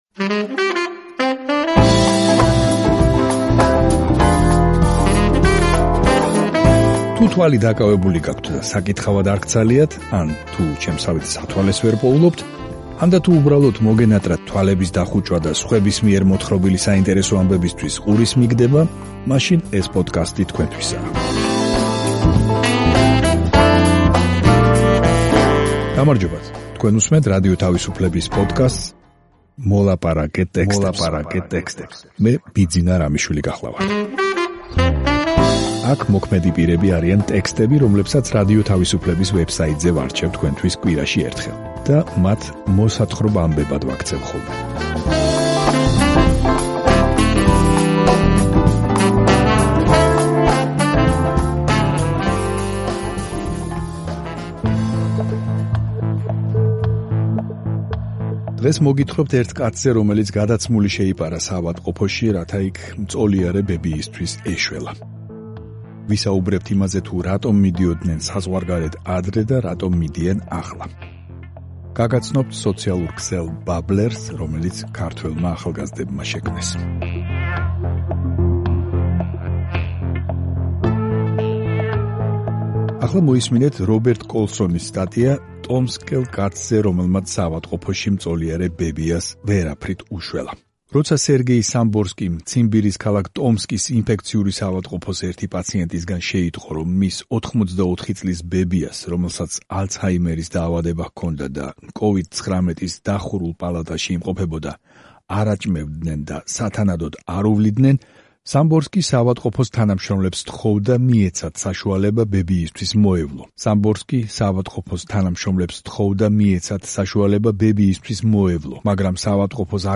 თუ თვალი დაკავებული გაქვთ და საკითხავად არ გცალიათ, ან თუ სათვალეს ვერ პოულობთ, ანდა, თუ უბრალოდ მოგენატრათ თვალების დახუჭვა და სხვების მიერ მოთხრობილი საინტერესო ამბებისთვის ყურის მიგდება, მაშინ ეს პოდკასტი თქვენთვისაა.